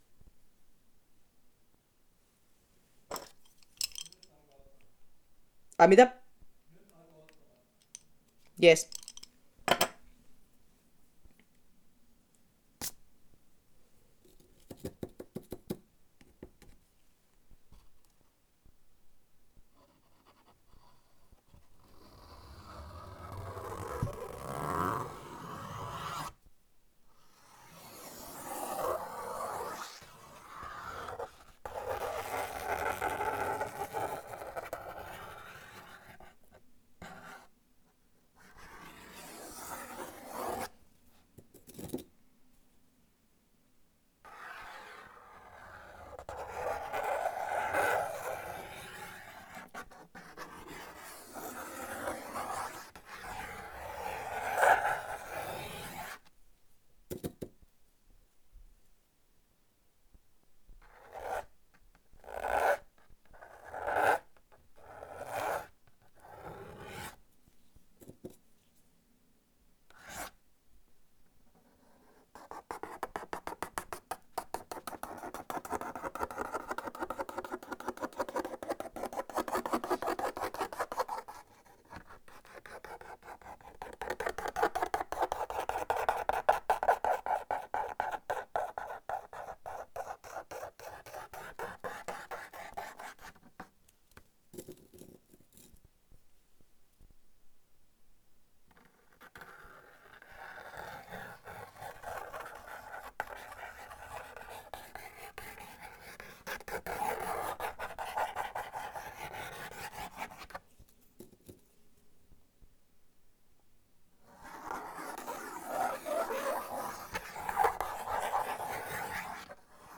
You can listen to the sketching and planning a street art piece in Kuopio. All sounds are hand made using pencil, sticks and ink and recorded during the sketching process.
Sketching and playing with sounds of the drawing.
Recorded at homestudio in Kuopio just before painting this piece,